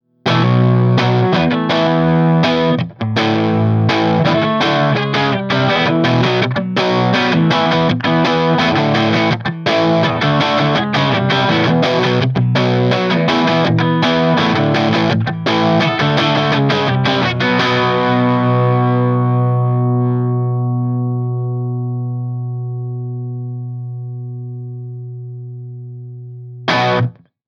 18 Watt v6 - EL84 Dirty Orange V30
Note: We recorded dirty 18W tones using both the EL84 and 6V6 output tubes.